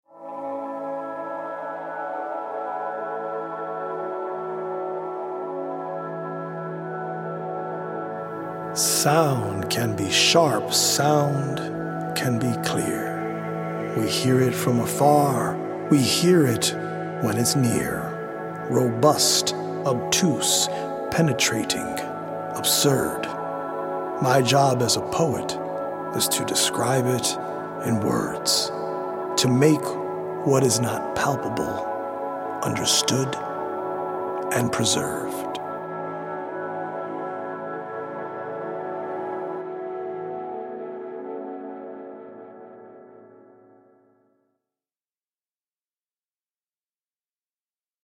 healing audio-visual poetic journey
as well as healing Solfeggio frequency music by EDM producer